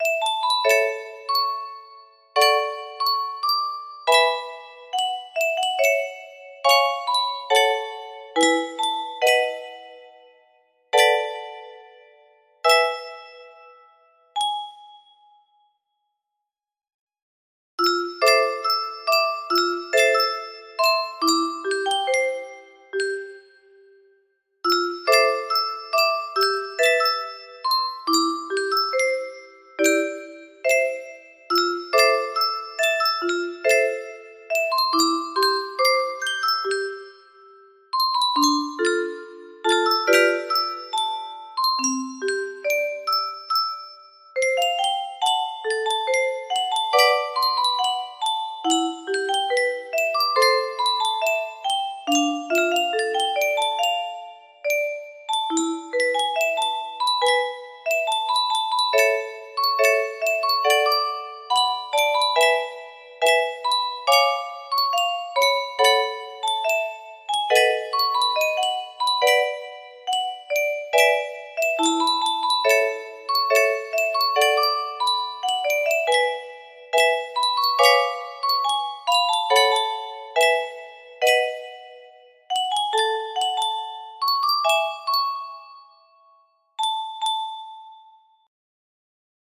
Unknown Artist - Untitled music box melody
Grand Illusions 30 (F scale)